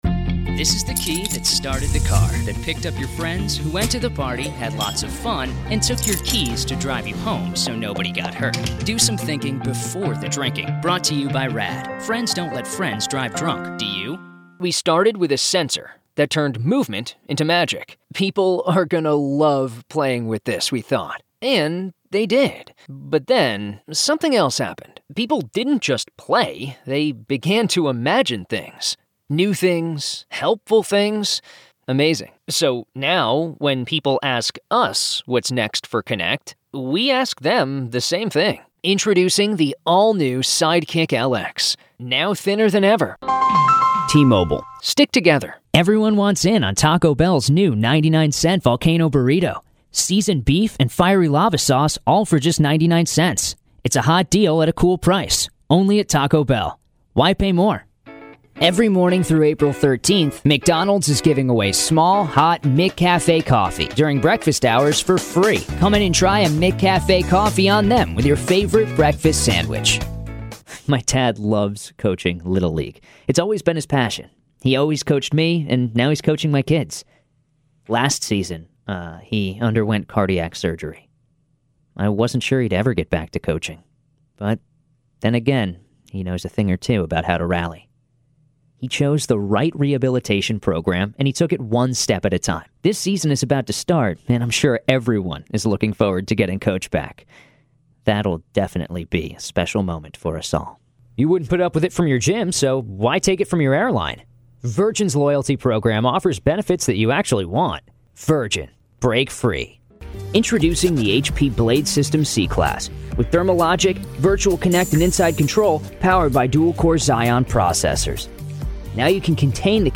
A youthful male voice with over 14 years in the voiceover industry.
Teen
Young Adult
I have a professional quality home studio, allowing me to be versatile, reliable, and provide high quality audio.
Non-traditional announcer, young male roles, student, hip, wry/sarcastic, casual voices. Youthful but sophisticated, comedic, articulate, charming, unique, natural, casual.